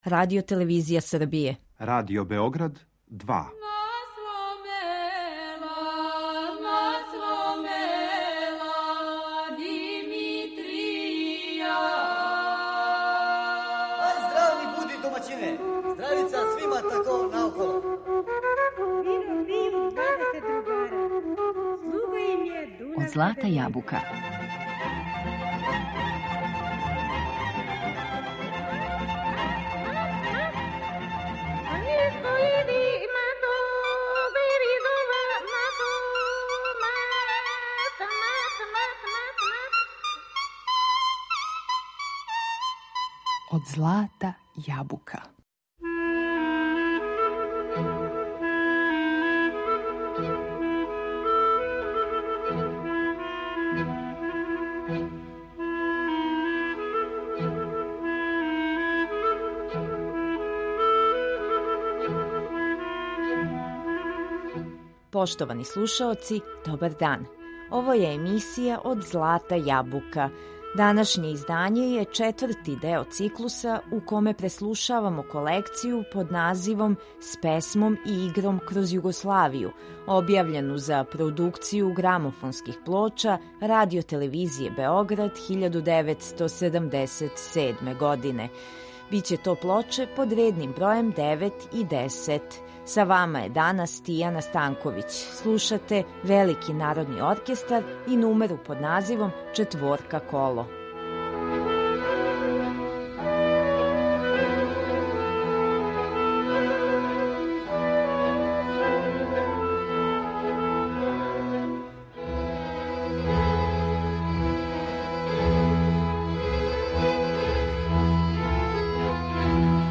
За данас смо припремили избор песама са последњих двеју плоча. Слушаћете снимке наших признатих вокалних солиста и ансамбала из различитих крајева тадашње државе.